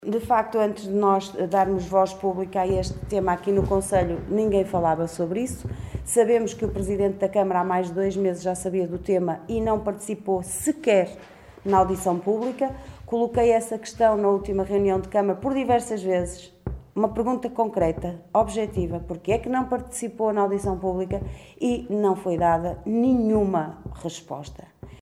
Liliana Silva na Conferência de imprensa desta manhã convocada pela OCP.